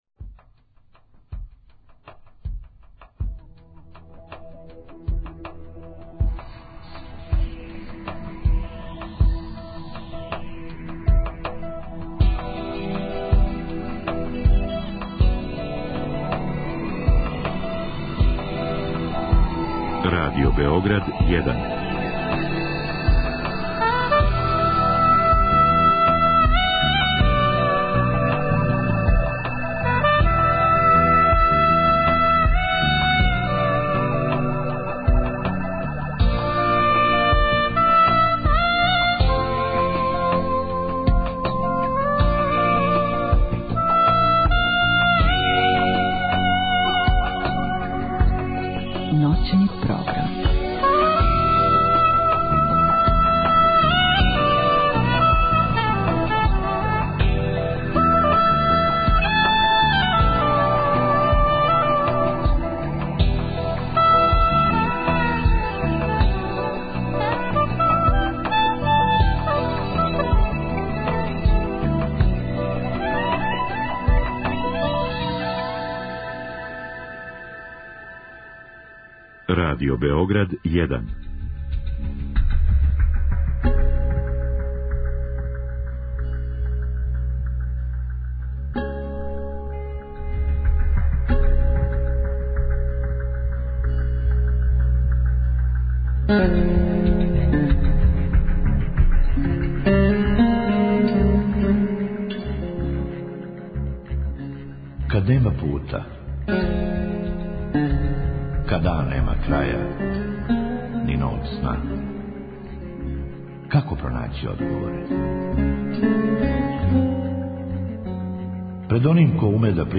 У другом сату емисије позивамо наше слушатељке и слушаоце да поделе са нама своје утиске, коментаре, питања и емотивне проблеме, као и предлоге тема о којима би волели да сазнају више.